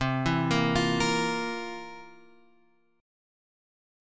C11 chord {8 7 8 x 6 6} chord